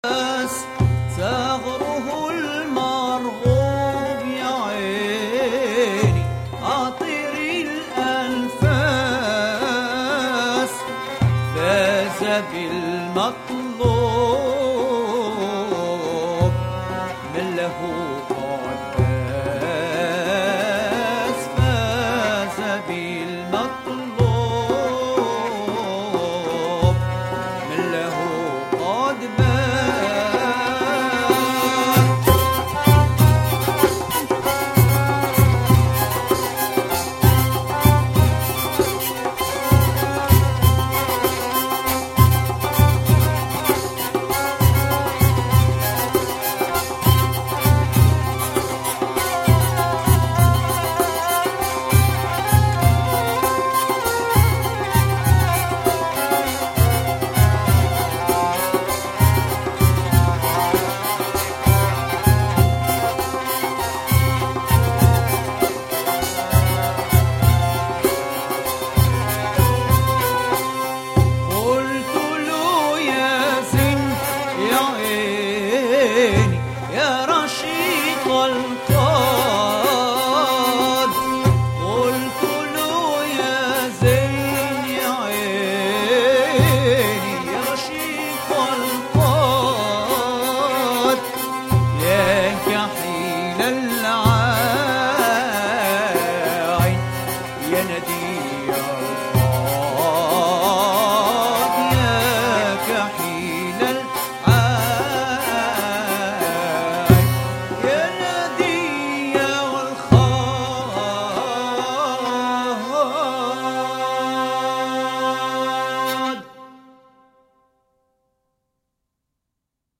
Musiques d´al Andalus